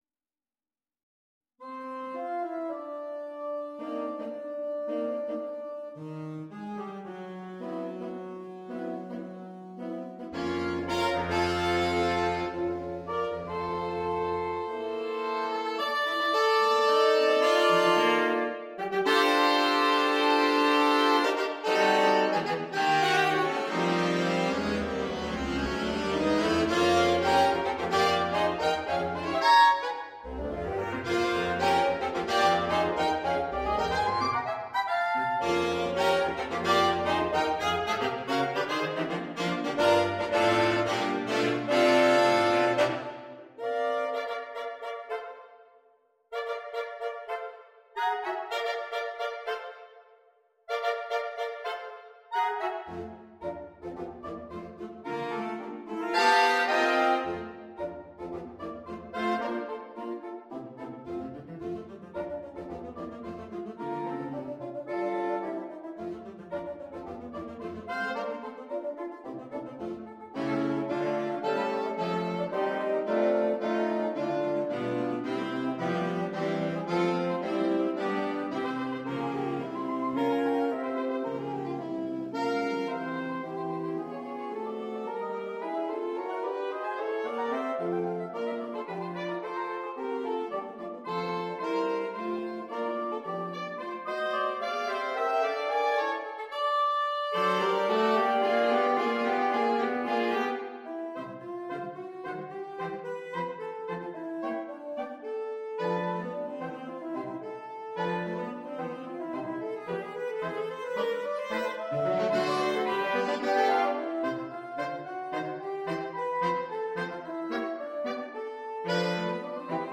для квинтета саксофонов